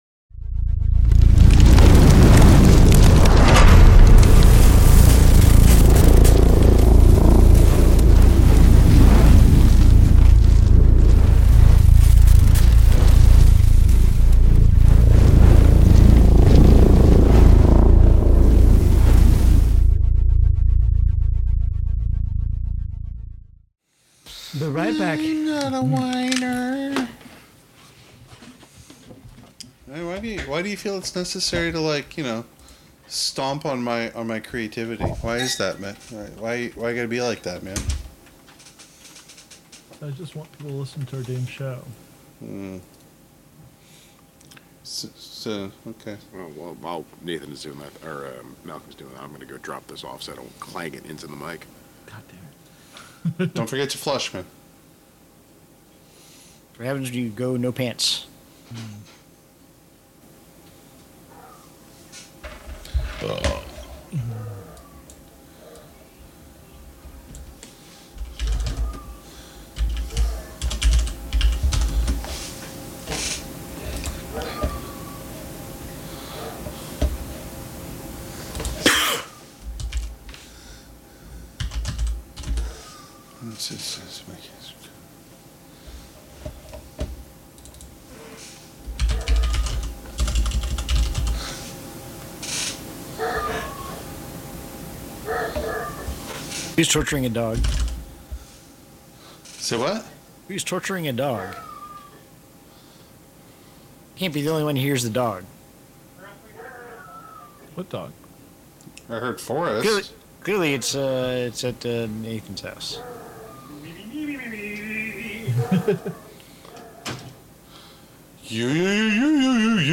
We wanted to pull back the curtain and share just a little of the content that never makes it into the episodes. Take a walk down memory lane to the night the gang decided to try to record the intros for our Dragonbane actual-play.